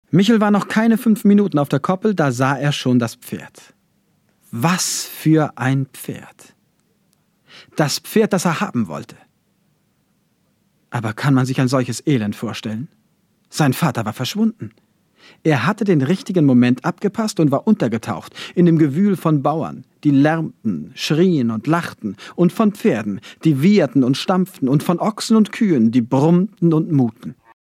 Hörbuch: Immer dieser Michel.
Immer dieser Michel. Hörspielklassiker Astrid Lindgren